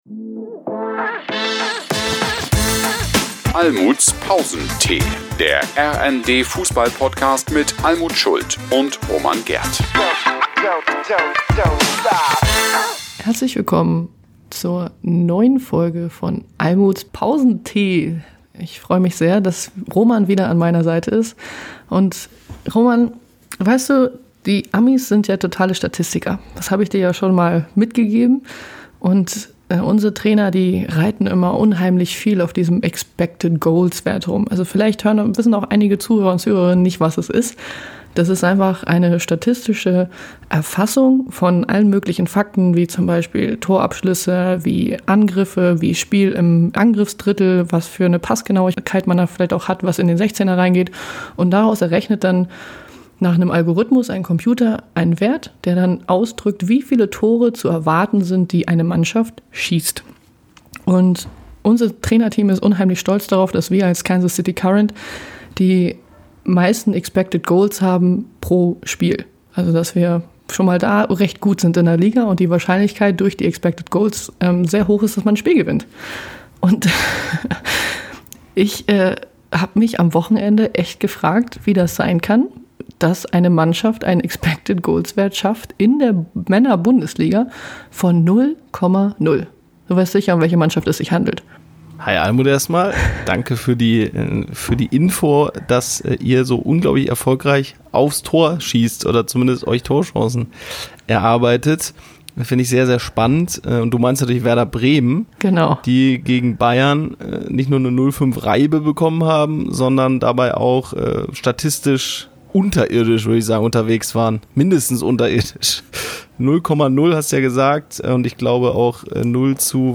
Der eine, Fuss, ist die wohl bekannteste Stimme im deutschen Fußball.